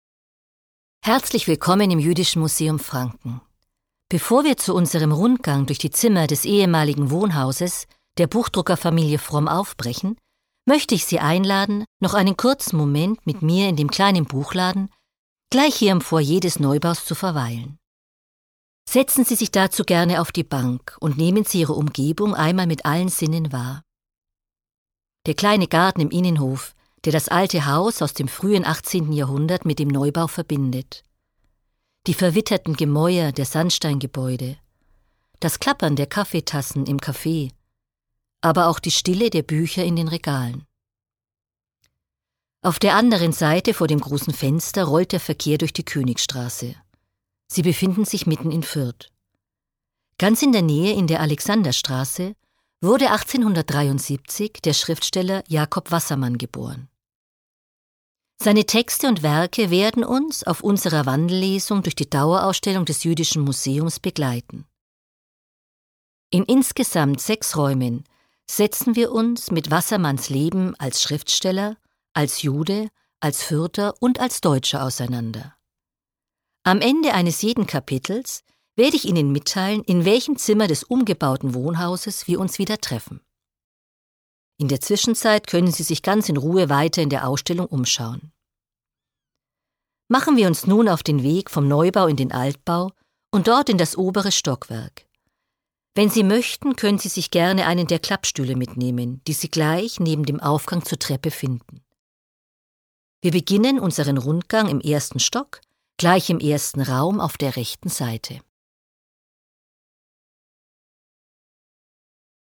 150 JAHRE JAKOB WASSERMANN. EIN AUDIOWALK